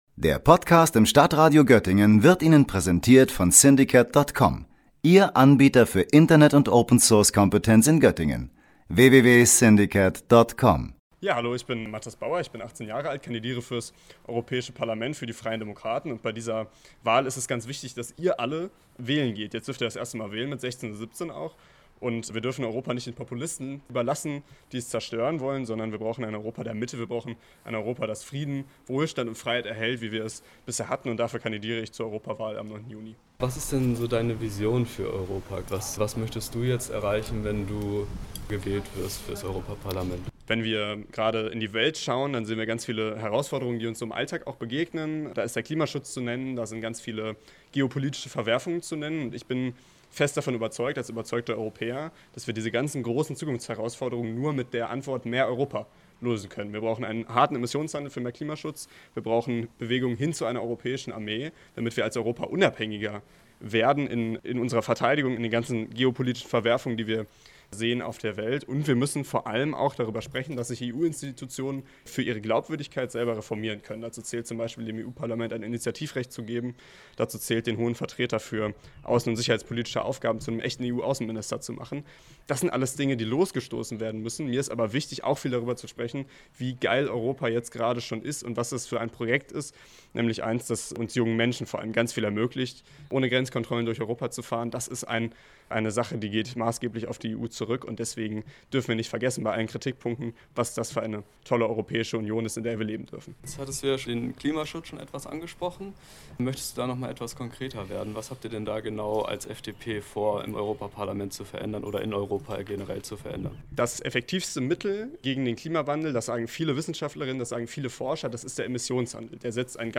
Für einen Überblick über die Parteien läuft in dieser Woche unsere Reihe „Parteien im Portrait“. Heute hören Sie ein Interview